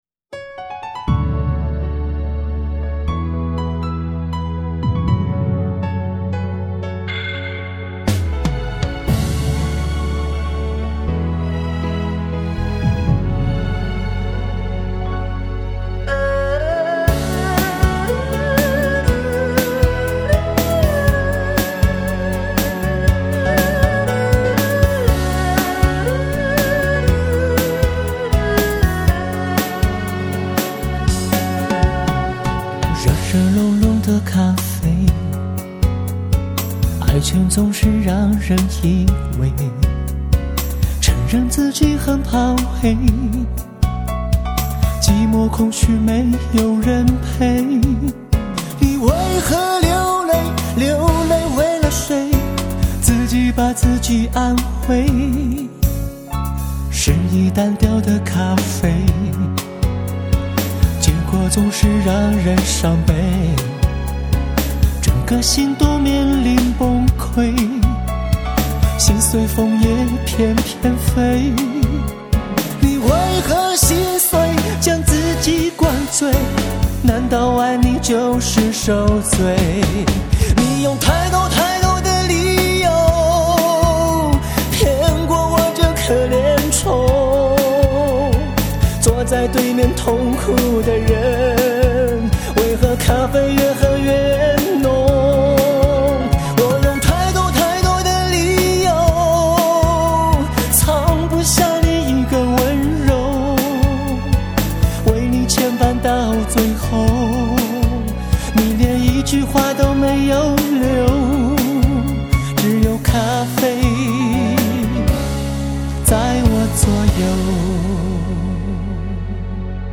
充满情调的闲适节奏，
整张专辑以抒情音乐为主，整体风格多元化，
音乐旋律上强调优美，节奏上强调律动，快慢有致、动静相宜。